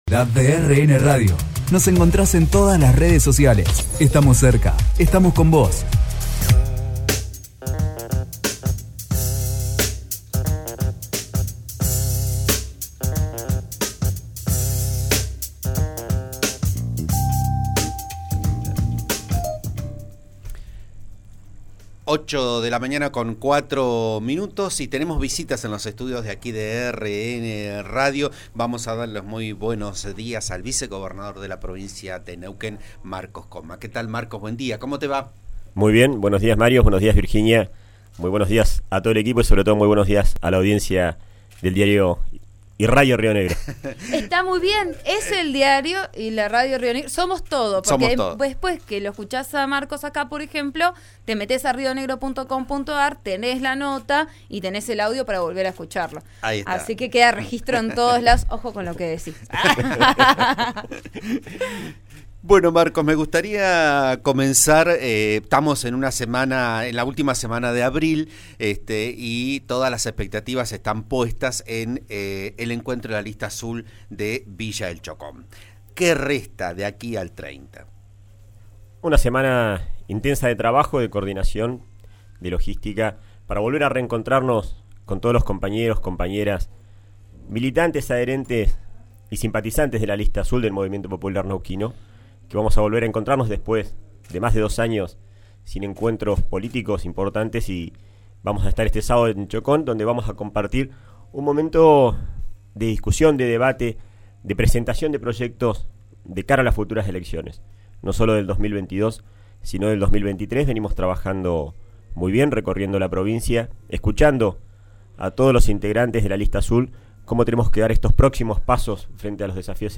También le resultó extraño la mención de un informe ambiental que desconocía así que aclaró ambos aspectos en Vos A Diario, por RN RADIO.